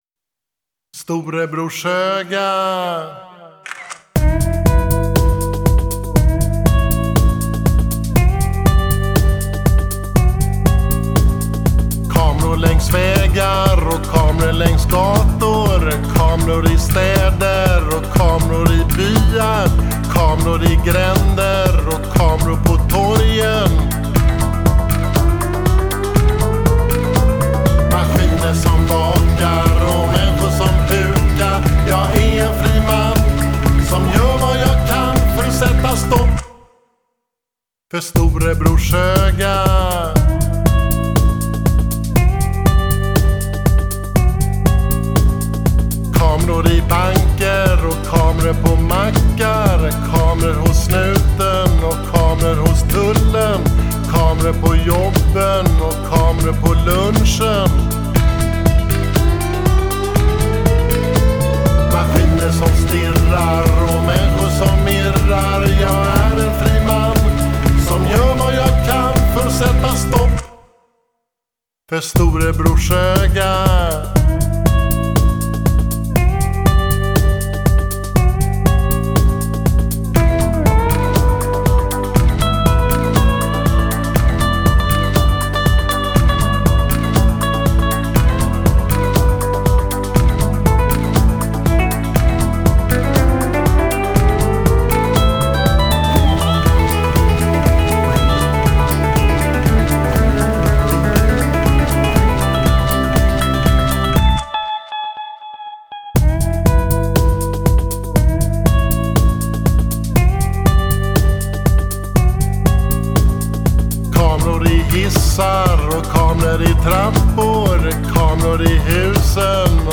Refrängen följde kort efter med nedåtgående basgång.